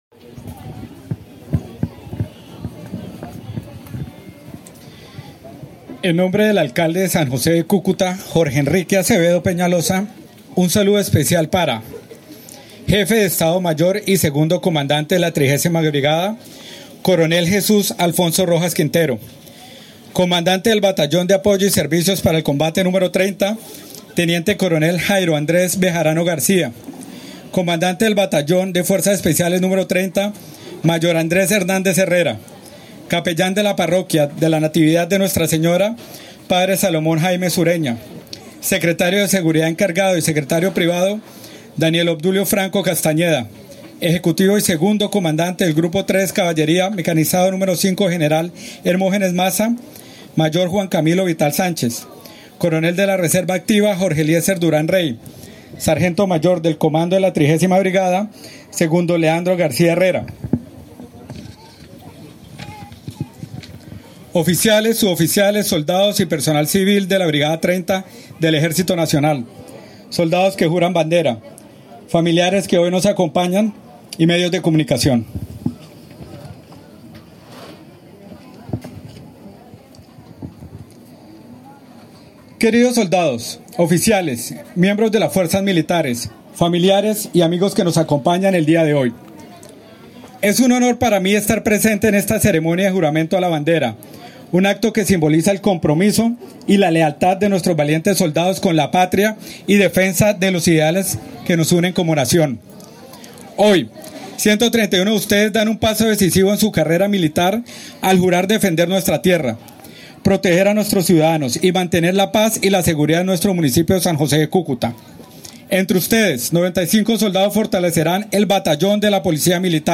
Alcalde-encargado-Juan-Bocanegra-habla-sobre-el-nuevo-contingente-de-soldados.mp3